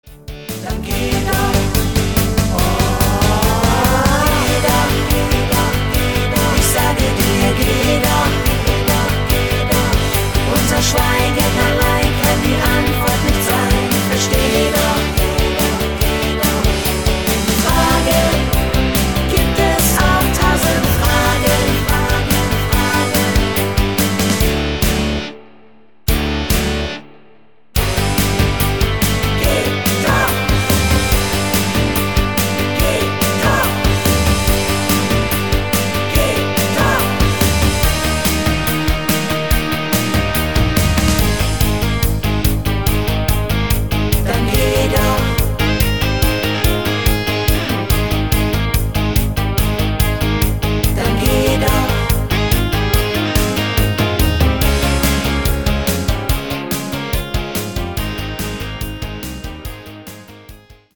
Rockschlager Download Buy
Rhythmus  Rock
Art  Deutsch, Deutsche Evergreens, Party Hits, Rock